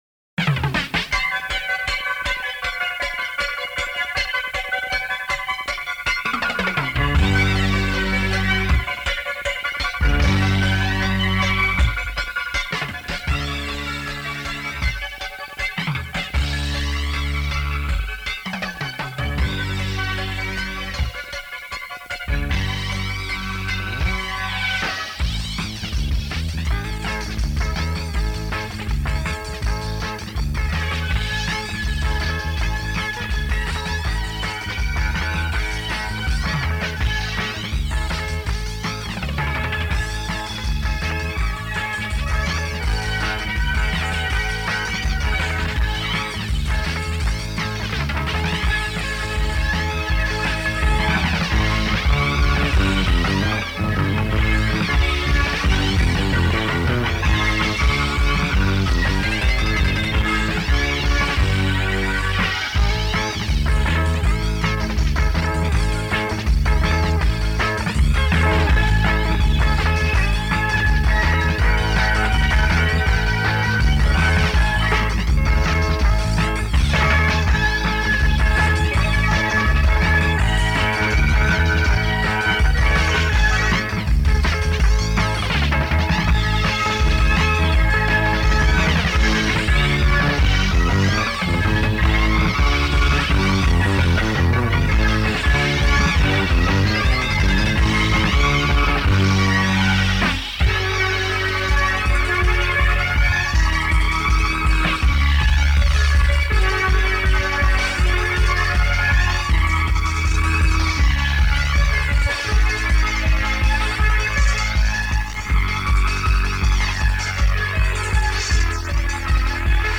Guitar
Keyboards
Bass Guitar
Drums
155  G  4/4